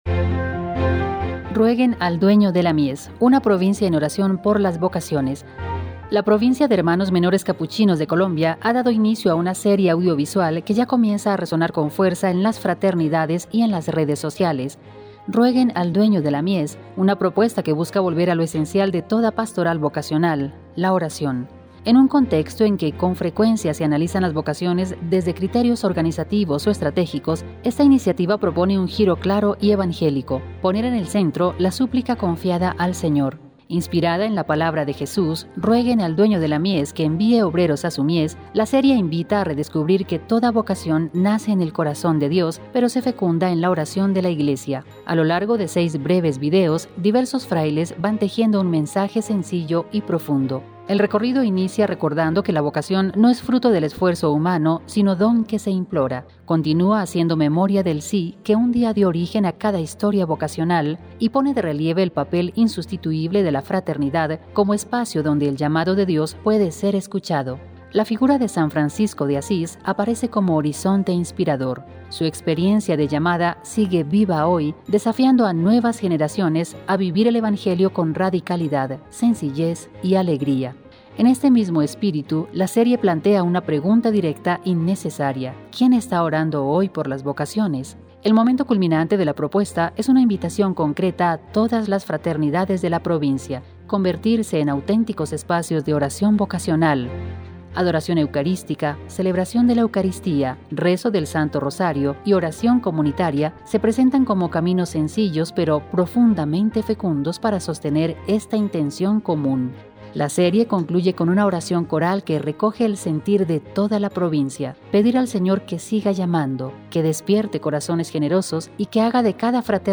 A lo largo de seis breves videos, diversos frailes van tejiendo un mensaje sencillo y profundo.
La serie concluye con una oración coral que recoge el sentir de toda la Provincia: pedir al Señor que siga llamando, que despierte corazones generosos y que haga de cada fraternidad un lugar de acogida donde los jóvenes puedan escuchar su voz y responder con valentía.